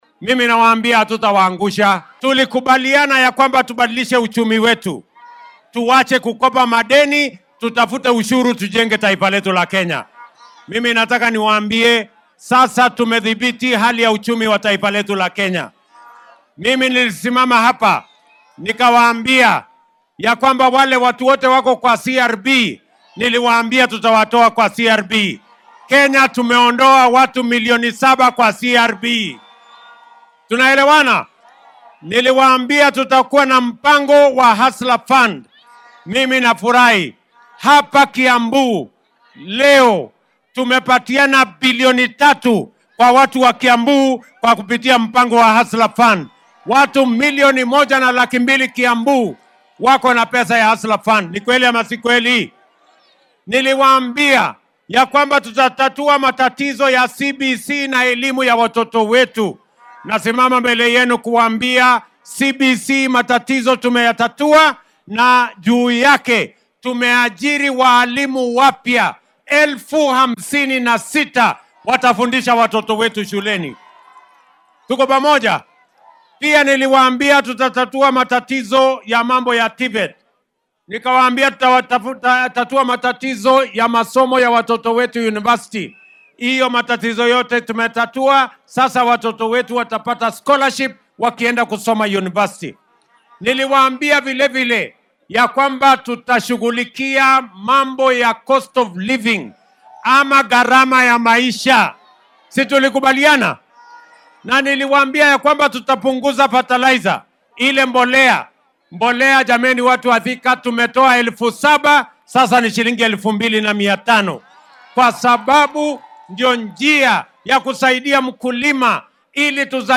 DHAGEYSO:Madaxweynaha dalka oo ka hadlay fulinta ballanqaadyadii uu u sameeyay Kenyaanka